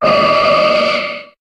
Cri de Gardevoir dans Pokémon HOME.